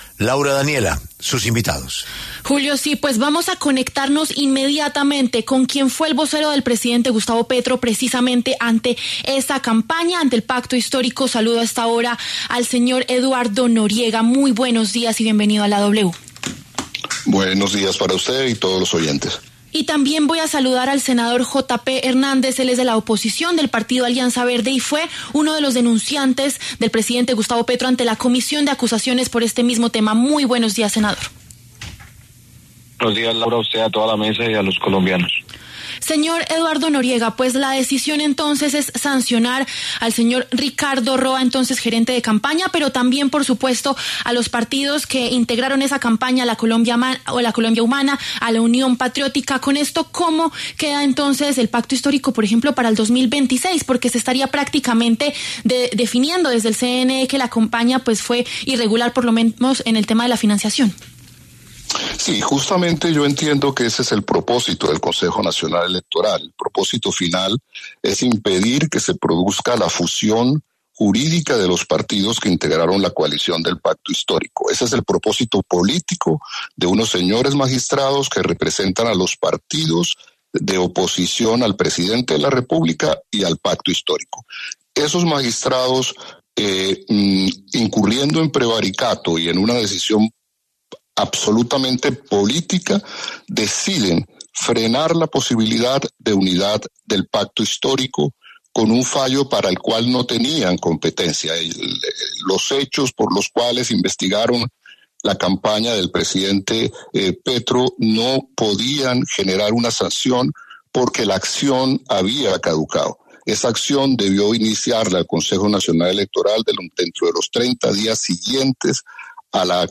¿Campaña Petro recibió financiación irregular o CNE emitió decisión política? Debaten Jota Pe y Pacto